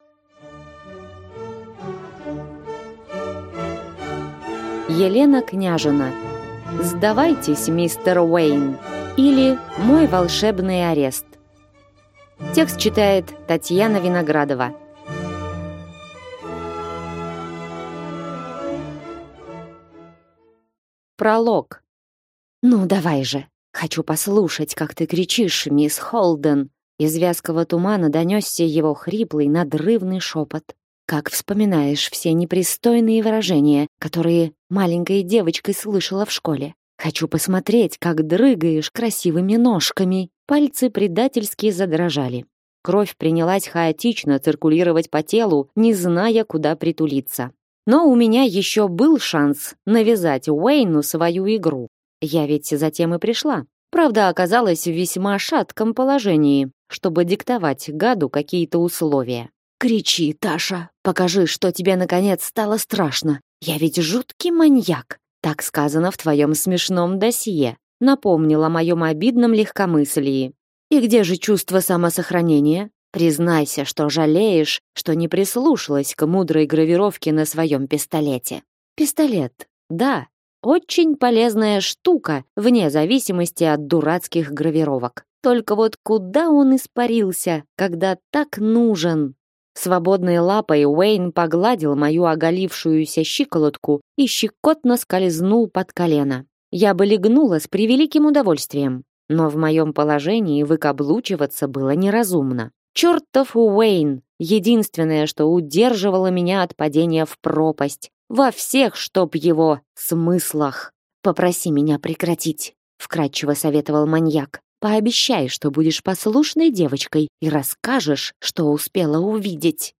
Аудиокнига Сдавайтесь, мистер Уэйн! или Мой волшебный арест | Библиотека аудиокниг